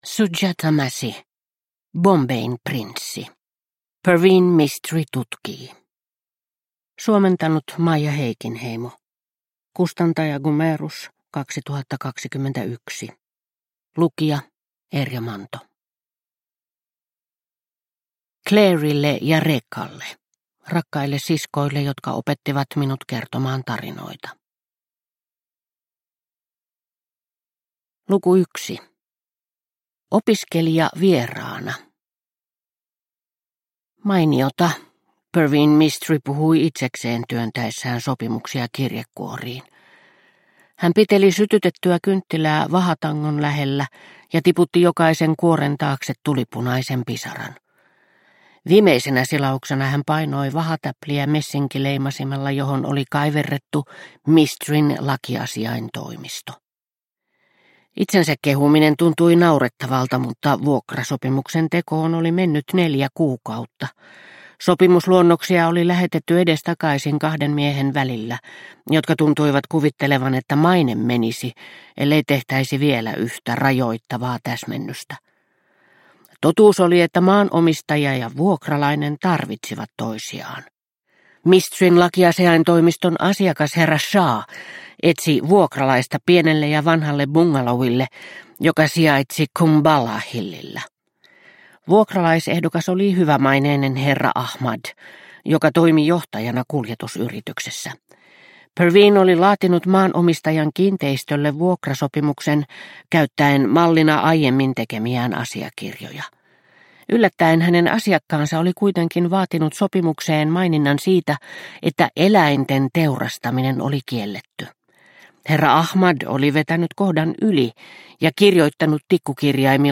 Bombayn prinssi – Ljudbok – Laddas ner